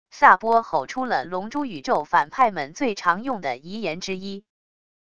萨波吼出了龙珠宇宙反派们最常用的遗言之一wav音频